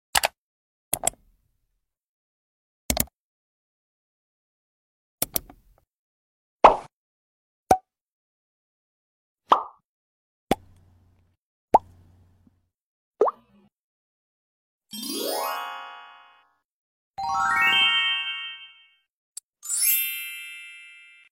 Here’s a sound effect pack!